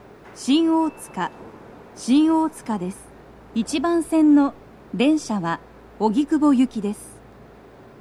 スピーカー種類 BOSE天井型
足元注意喚起放送の付帯は無く、フルの難易度は普通です
到着放送1